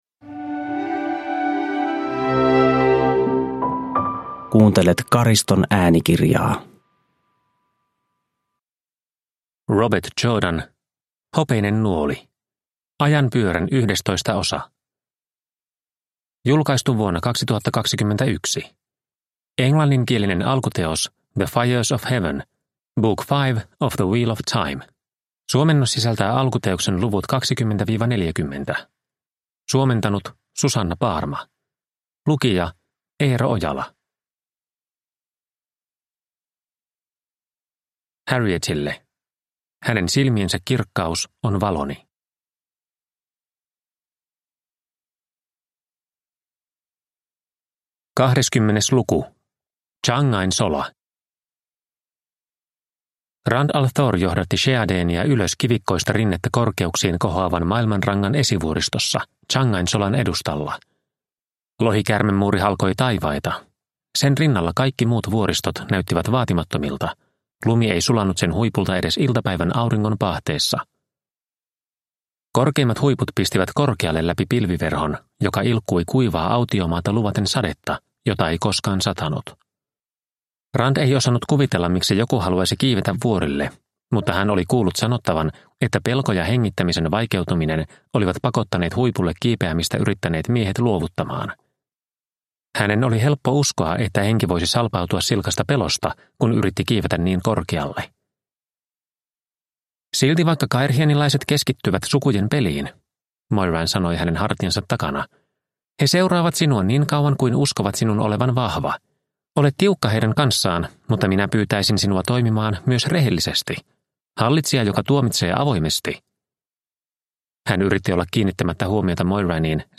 Hopeinen nuoli (ljudbok) av Robert Jordan